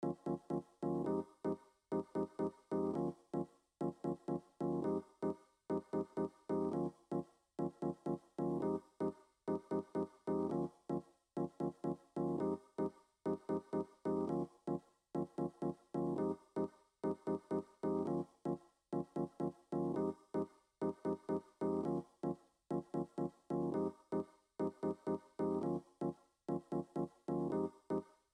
rhodes_loop_ppal_1.wav